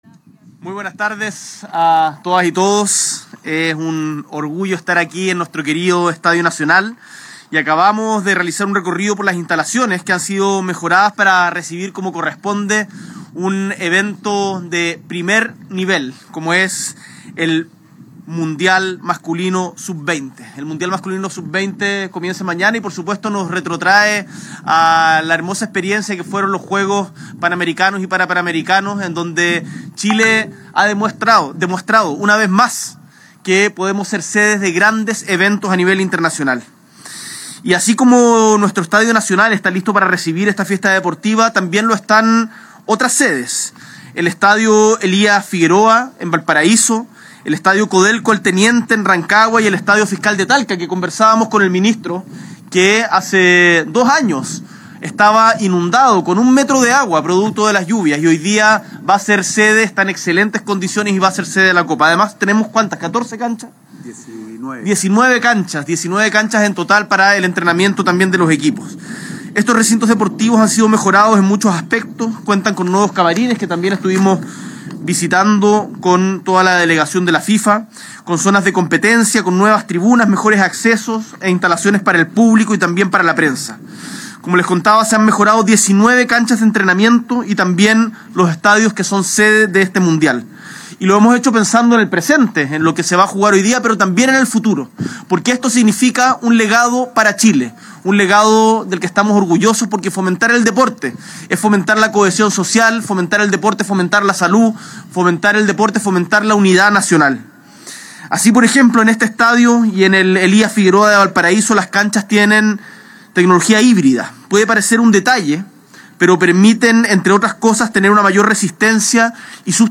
S.E. el Presidente de la República, Gabriel Boric Font, visita el Estadio Nacional previo a la inauguración del Mundial Sub20 de la Fifa Chile 2025